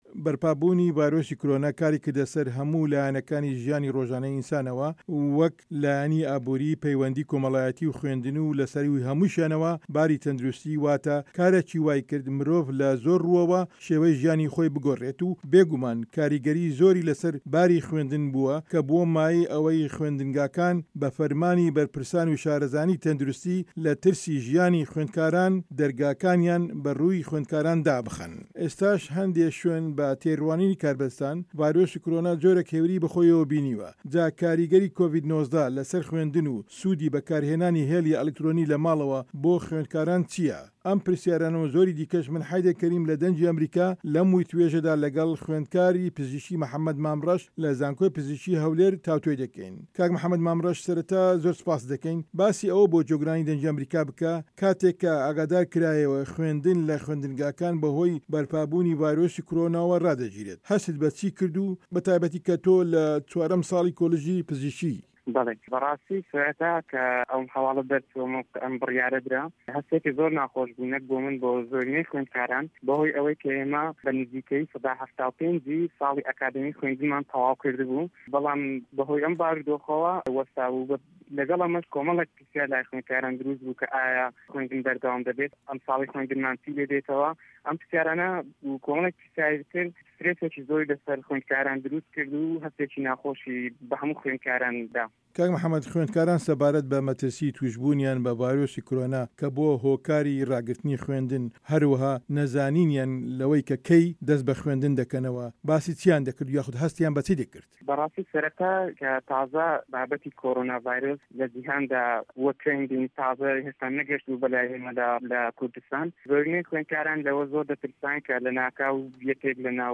خوێندکارێکی پزیشکی باس لە کاریگەرییەکانی ڤایرۆسی کۆرۆنا لەسەر خوێندن دەکات